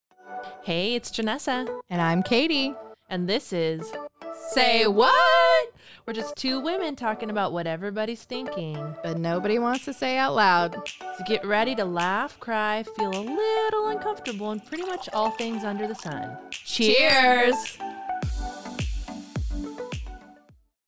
Just two chicks talking about everything everyone wants to say but doesn't!